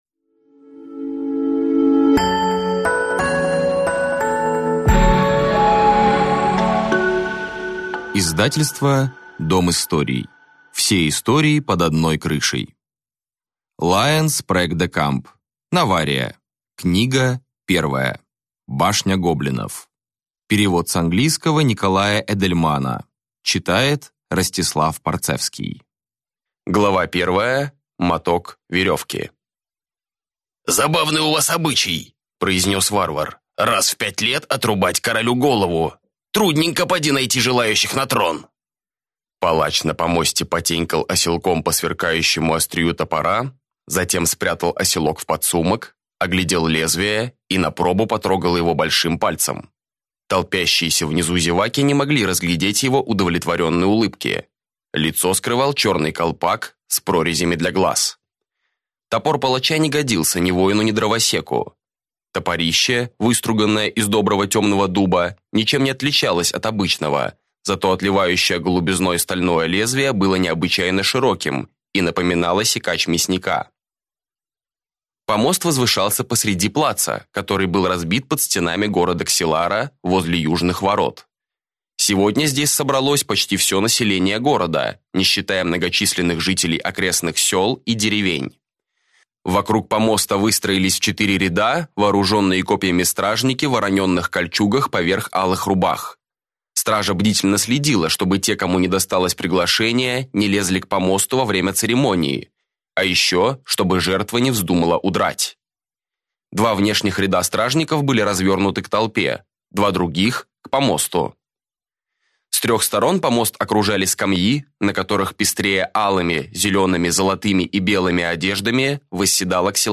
Аудиокнига Башня гоблинов | Библиотека аудиокниг
Прослушать и бесплатно скачать фрагмент аудиокниги